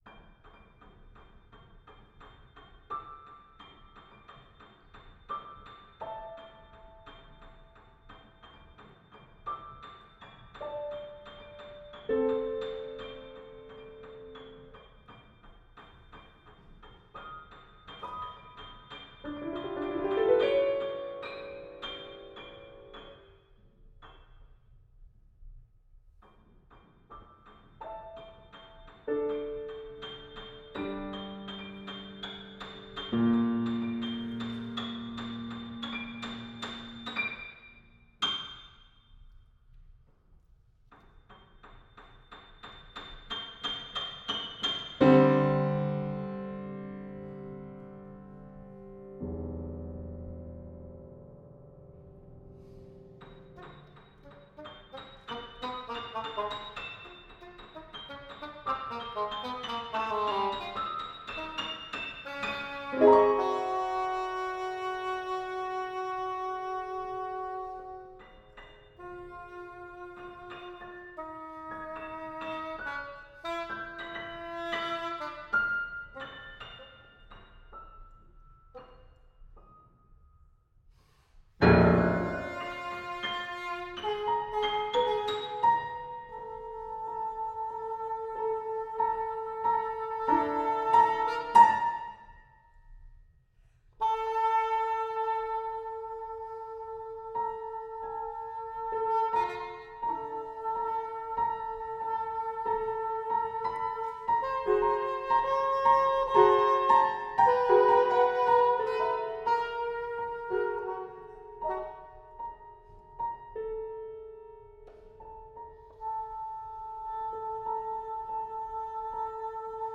full-length sonata